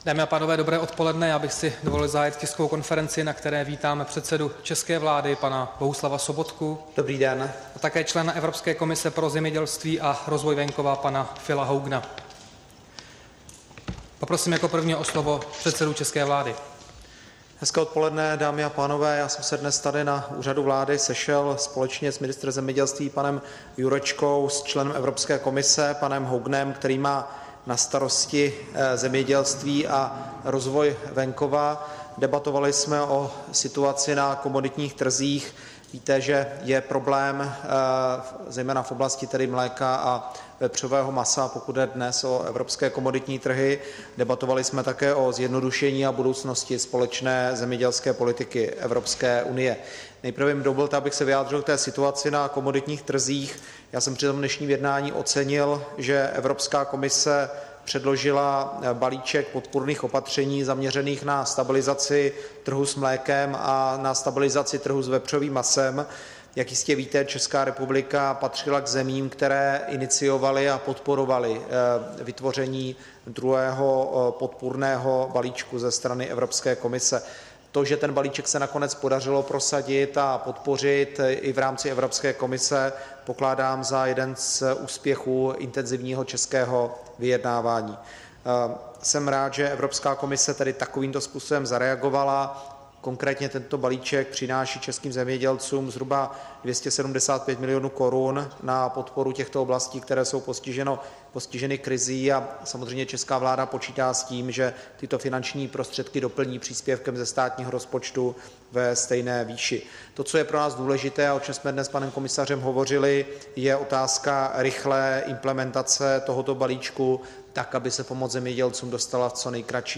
Tisková konference po jednání předsedy vlády Bohuslava Sobotky s komisařem Evropské komise Philem Hoganem 9. září 2016